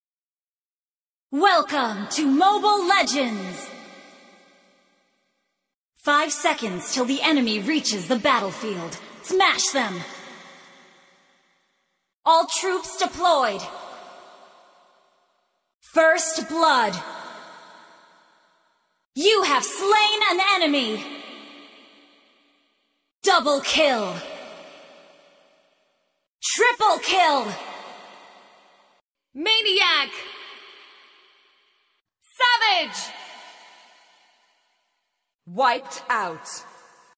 mobile legends battle sound #4upage sound effects free download